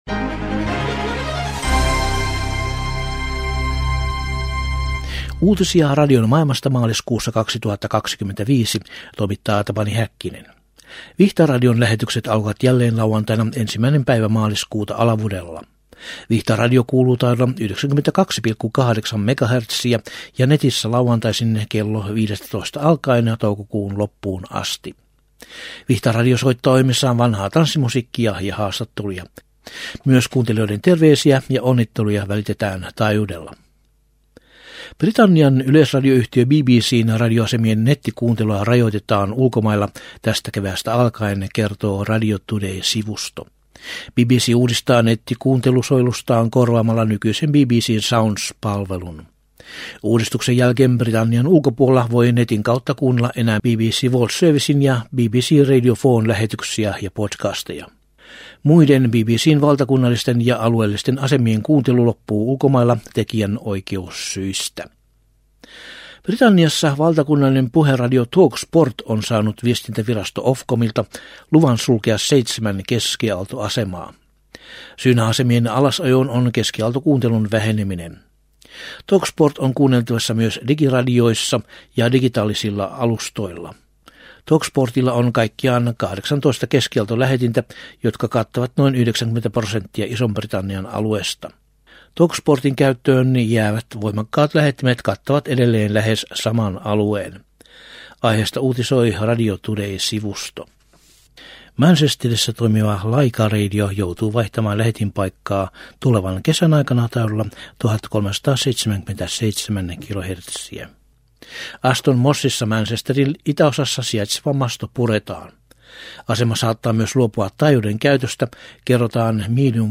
Maaliskuun 2025 uutislähetyksessä aiheina ovat muun muassa Vihtaradion paluu radiotaajuudelle kevään ajaksi, tulevat rajoitukset BBC:n radiokanavien nettikuuntelussa ja Luxemburgin maineikkaan radioaseman mastojen purkaminen.